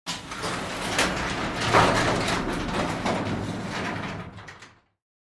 Garage Door
fo_garagedr_sm_open_01_hpx
Large and small garage doors are opened and closed.